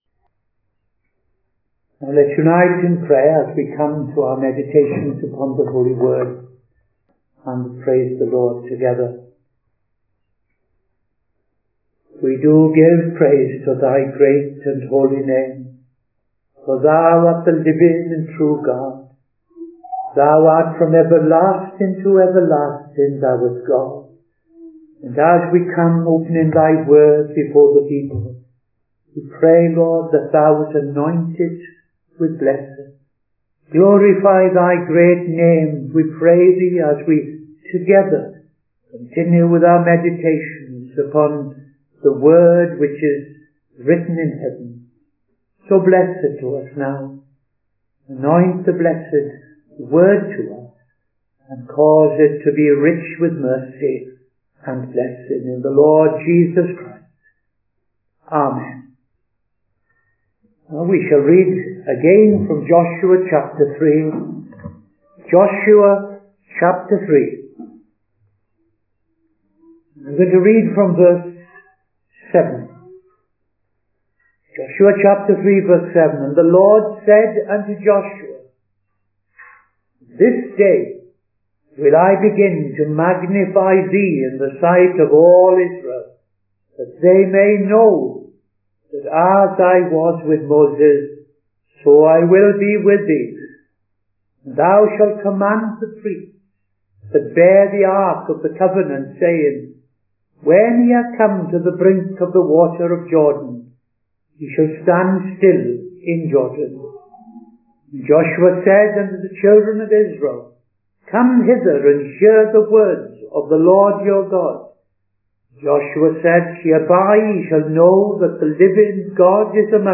Midday Sermon - TFCChurch
Midday Sermon 23rd November 2025